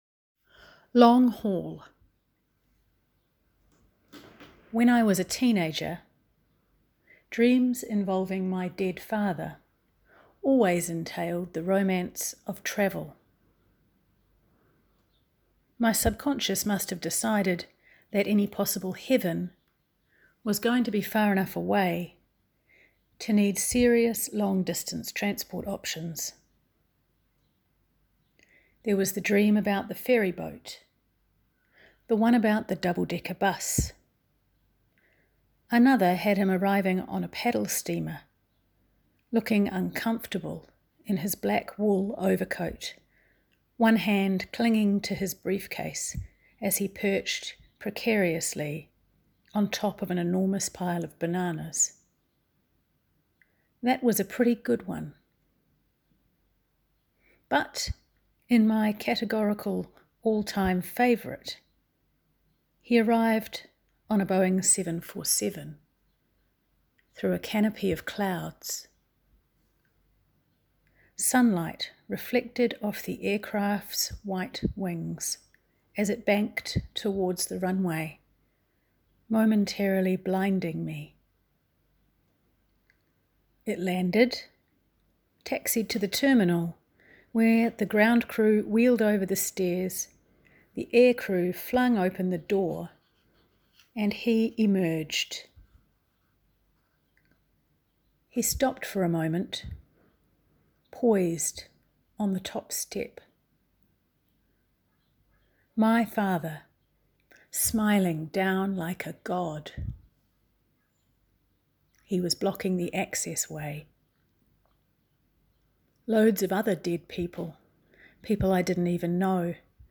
Poetry Shelf audio poem